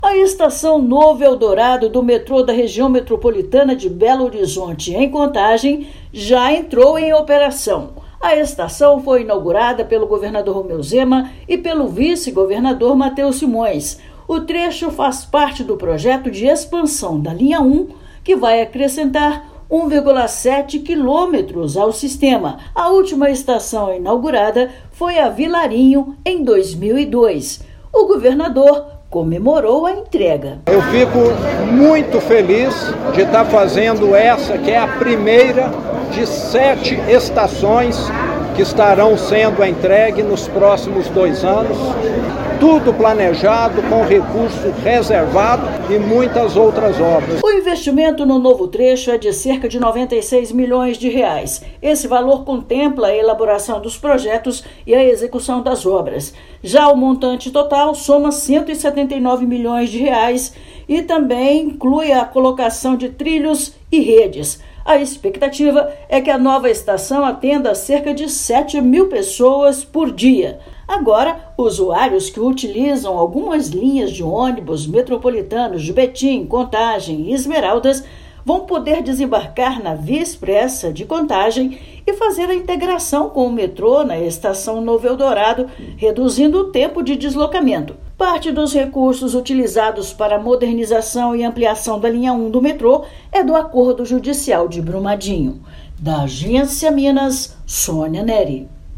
O novo terminal, que faz parte da Linha 1, deve atender cerca de 7 mil usuários por dia. Ouça matéria de rádio.